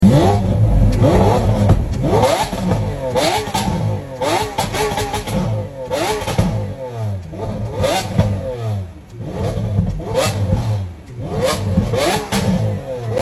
Redlining A Lamborghini Aventador SVJ sound effects free download